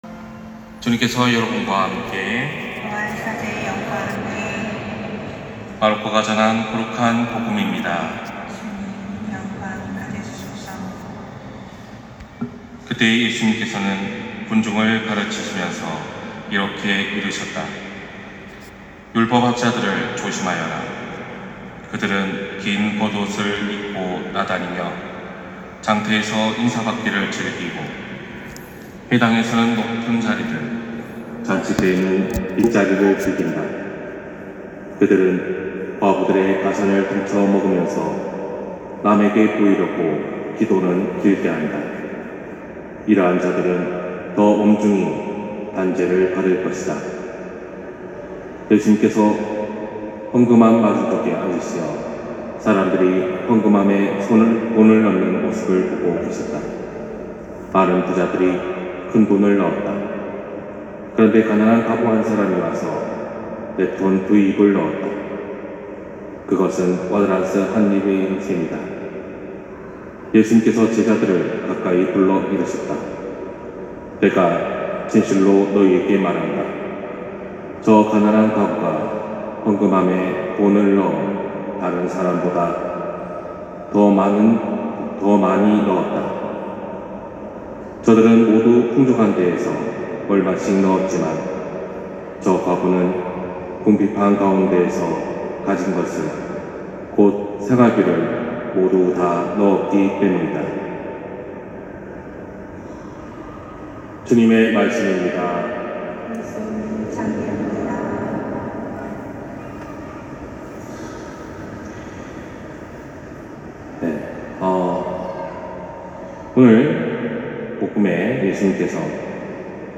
241109 신부님 강론 말씀